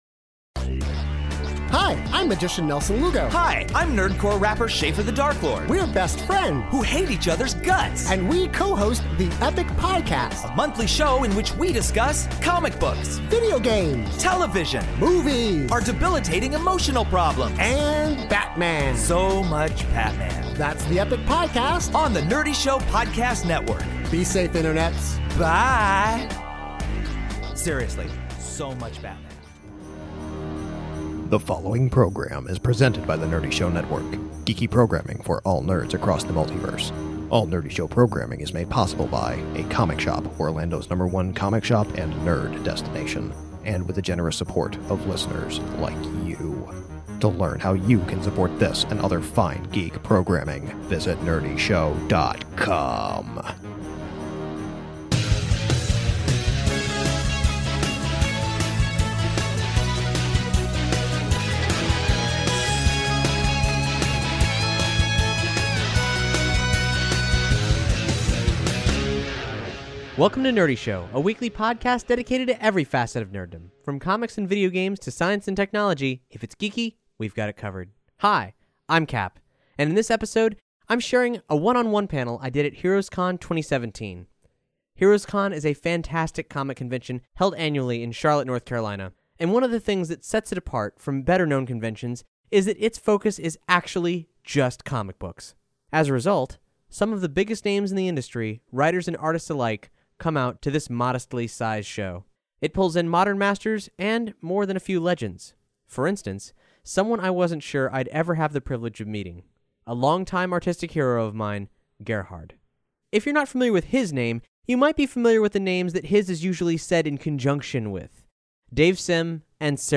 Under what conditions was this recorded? This archival HeroesCon panel is a one-on-one conversation